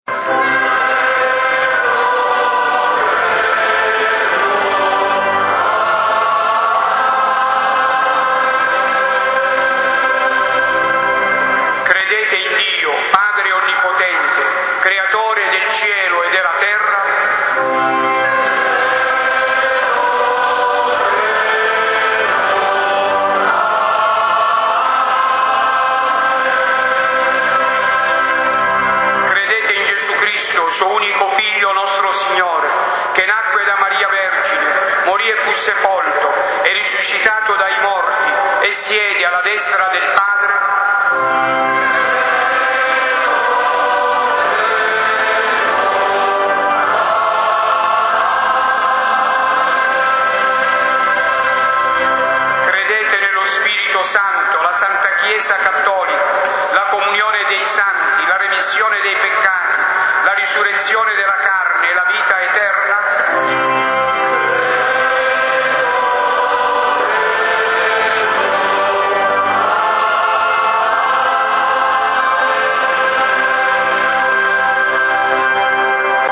Gallery >> Audio >> Audio2013 >> Ingresso Arcivescovo Pennisi >> mp3-Credo Apostolico